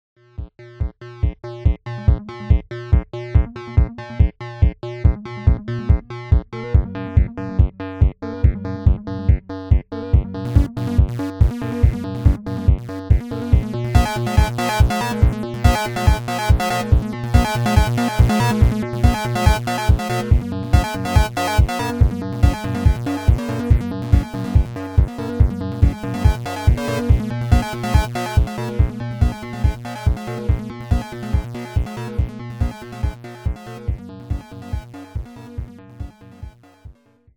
The synthesizer engine takes the MIDI notes and produces audio signals for each midi channel from generic functions, tone banks, or DDS-based.
The Synthesizer operates totally pipelined and with 50MHz clock speed and processes 128 from 256 possible MIDI channels, has 3 oscillators / wave generators and 256 voices polyphony.
spartan fpga synthesizer demo.wma